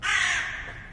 描述：两个来自愤怒的杰伊的电话。理想的铃声，如果你喜欢那东西的话。BP4025话筒，MixPre和FR2LE录音机。
声道立体声